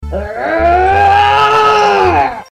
grunt